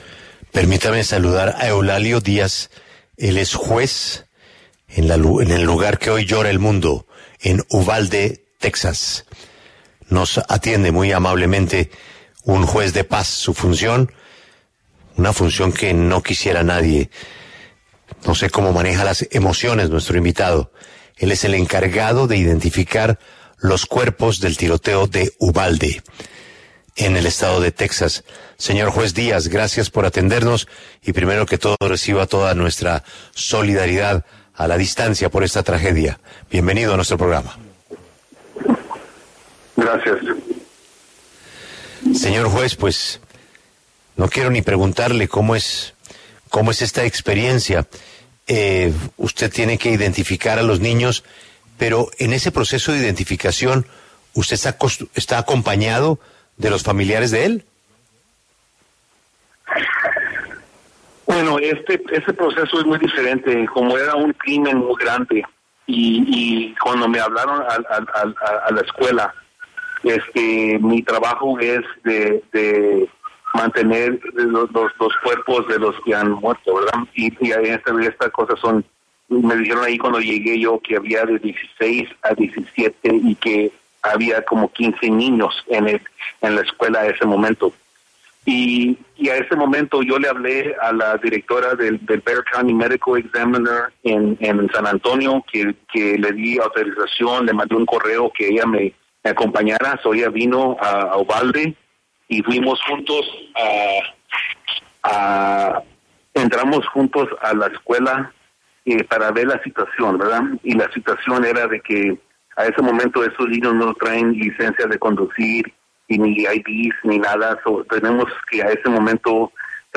Eulalio Díaz, juez de Paz que se encargó de identificar los cuerpos de las 21 víctimas del tiroteo en una escuela en Uvalde, Texas, habló en La W sobre el caso.
Tras el tiroteo reportado en una escuela en la localidad de Uvalde, Texas, Estados Unidos, La W conversó con el juez encargado de identificar los cuerpos de las 21 víctimas de la tragedia, entre ellas 19 niños y niñas.